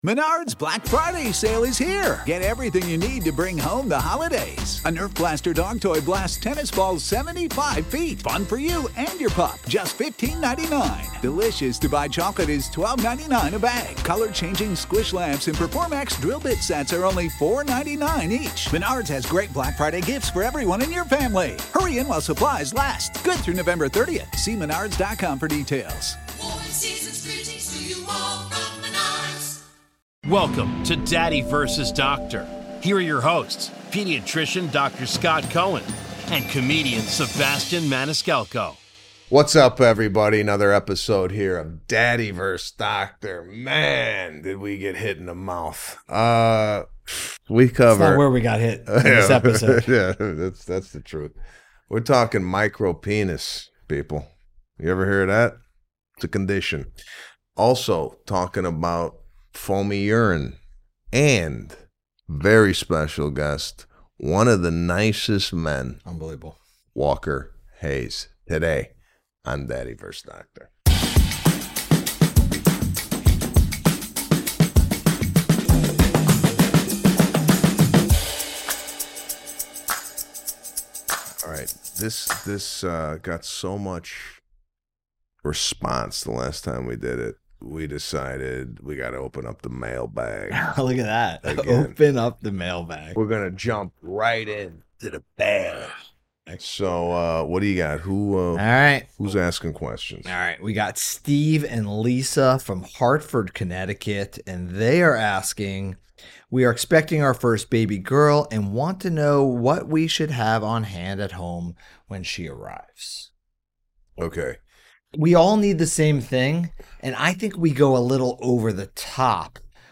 Micropenises, Urination, Baby & Rectal Thermometers and special guest caller Walker Hayes all on this week's episode of Daddy vs. Doctor!